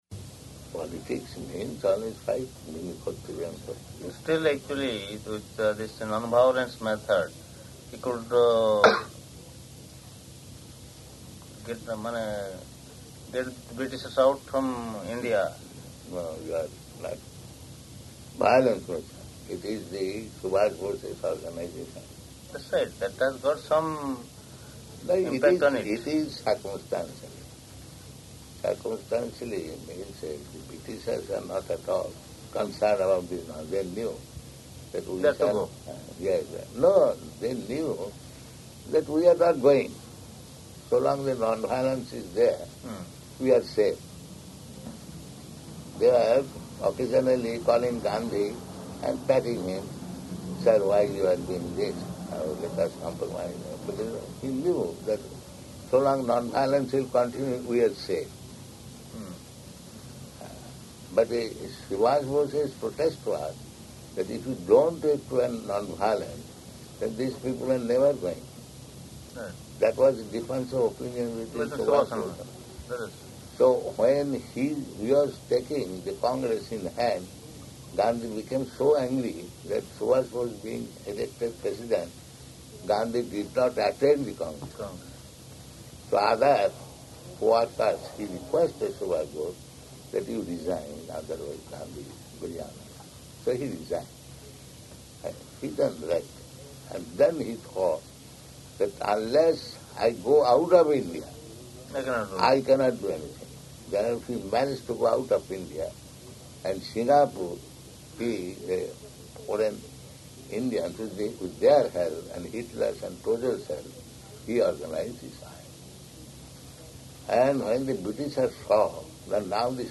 Room Conversation with Two Indian Guests
Type: Conversation
Location: Jagannātha Purī